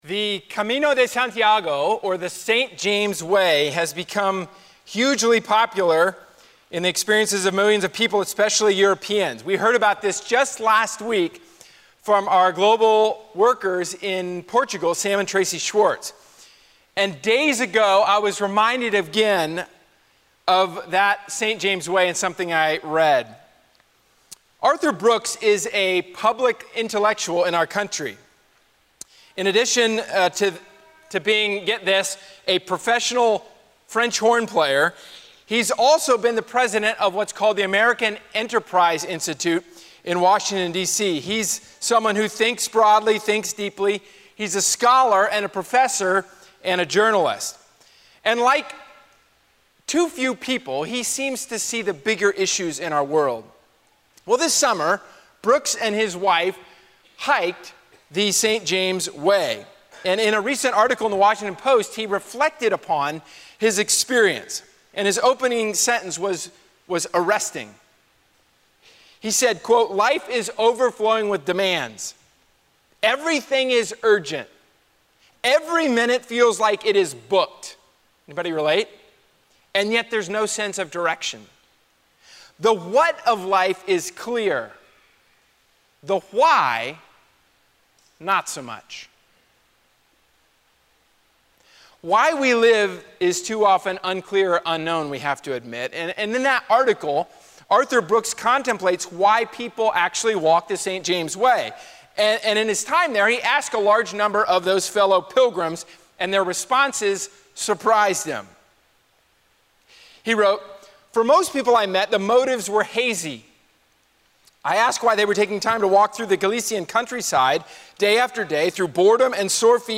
A sermon from the series "Called to the Gospel."